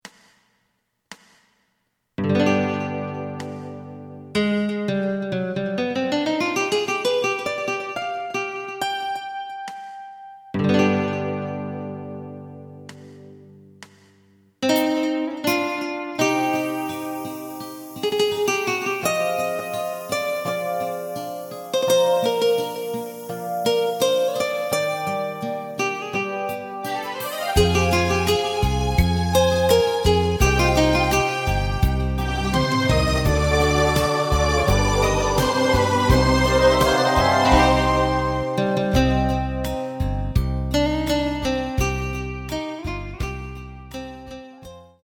エレキバンド用カラオケCD製作・販売
すべての主旋律を１人で演奏するスタイルにアレンジしてみました。
●フルコーラス(デモ演奏) メロディライン＋伴奏が演奏されます。